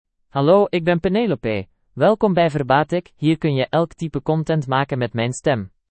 Penelope — Female Dutch (Belgium) AI Voice | TTS, Voice Cloning & Video | Verbatik AI
Penelope is a female AI voice for Dutch (Belgium).
Voice sample
Listen to Penelope's female Dutch voice.
Female
Penelope delivers clear pronunciation with authentic Belgium Dutch intonation, making your content sound professionally produced.